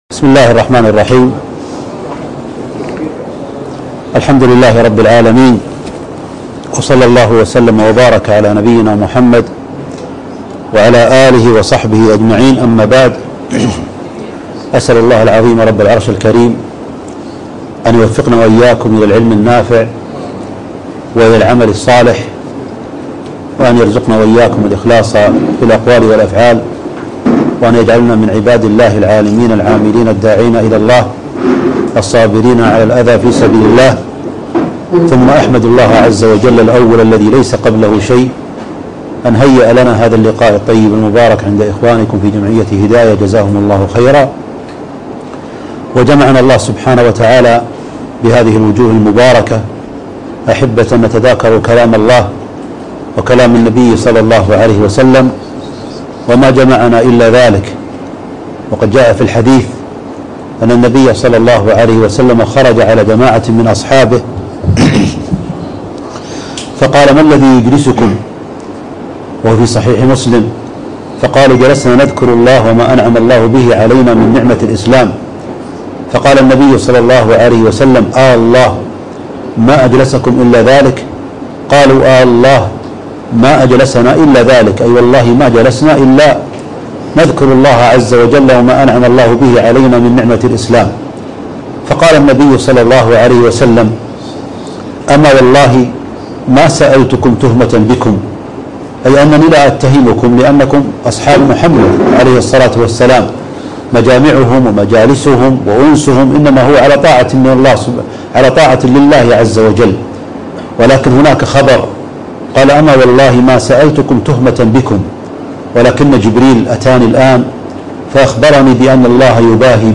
اللقاء المفتوح - بمقر جمعية الهداية الخيرية (1444)